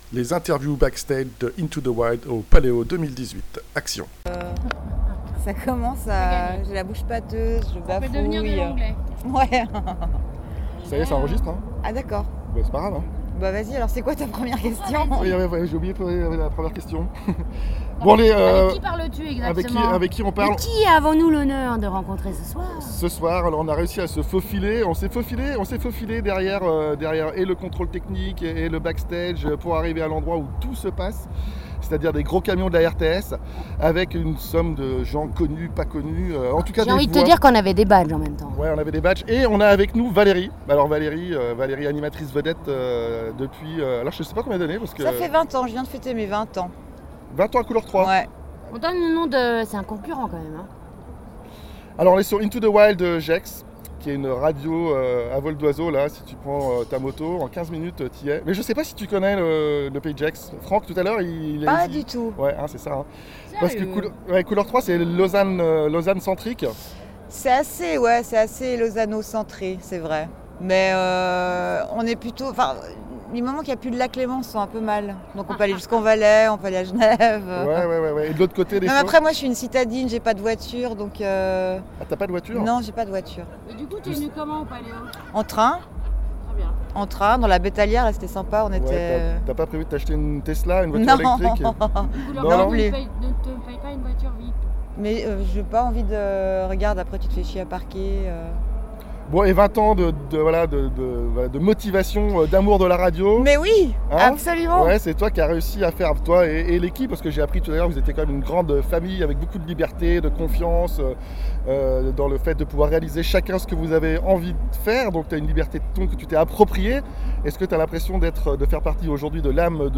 Interview backstage Paléo 2018